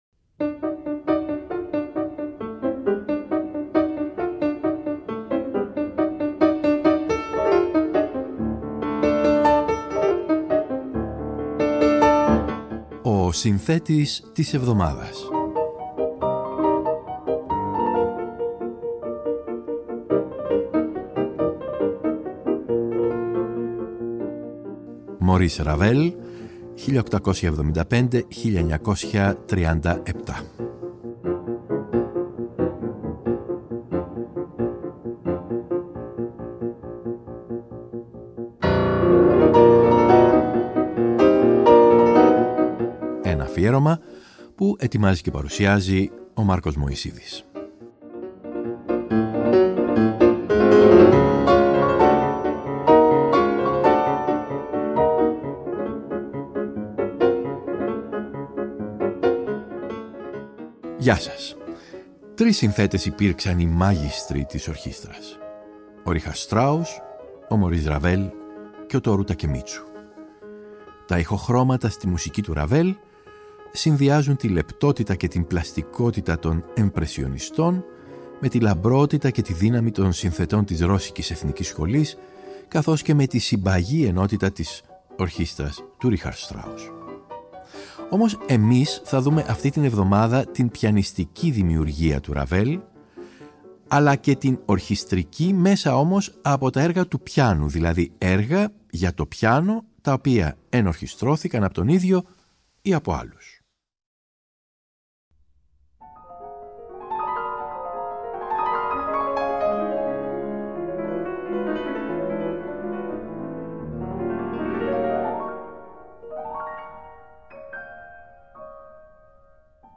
Όμως εμείς θα δούμε αυτή την εβδομάδα την πιανιστική δημιουργία του Maurice Ravel καθώς και την ορχηστρική, αλλά μέσα από τα έργα του πιάνου, δηλαδή έργα για το πιάνο τα οποία ενορχηστρώθηκαν από τον ίδιο ή από άλλους.